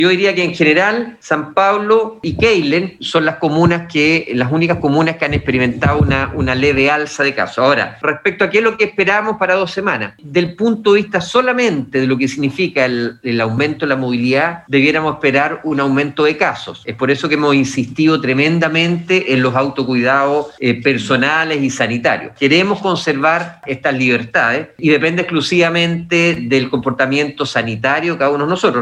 El seremi de Salud, Alejandro Caroca, dijo que es previsible que se produzca un aumento de casos durante las próximas semanas, como secuela de las mayores libertades.